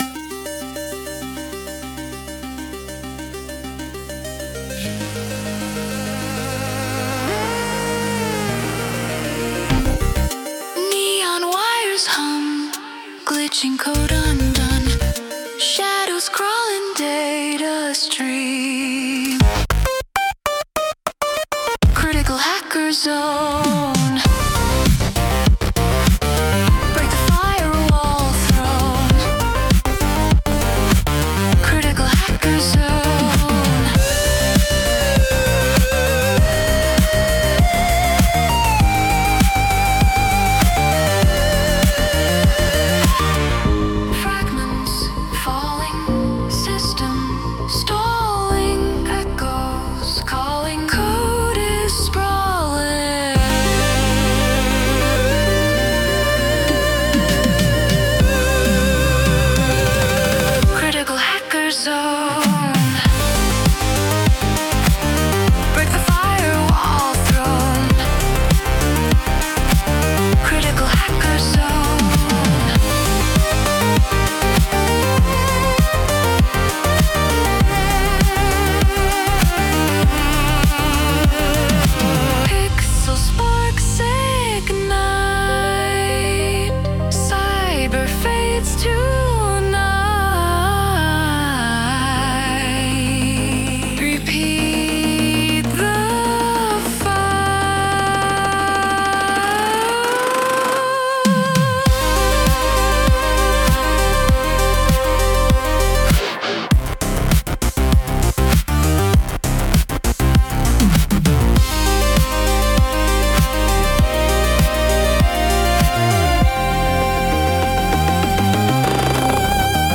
synthwave soundtrack
Genre: Synthwave / Cyberpunk / Retro Electronic